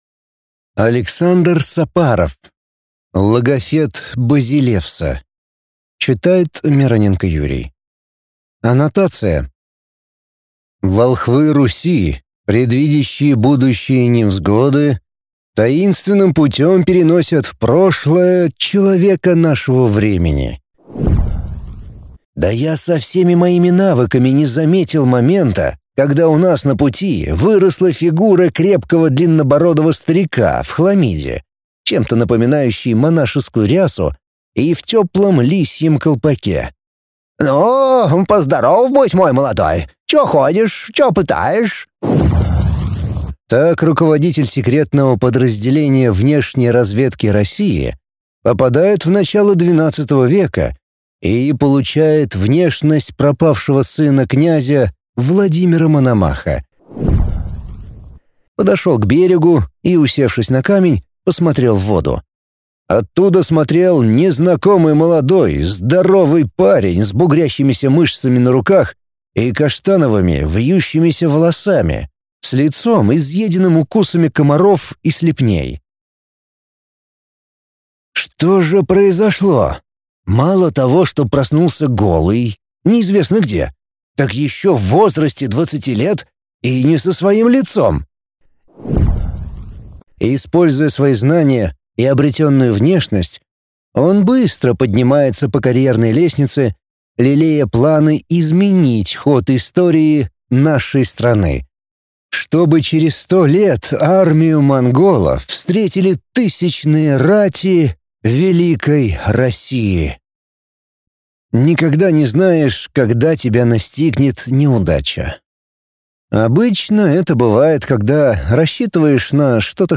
Аудиокнига Логофет базилевса | Библиотека аудиокниг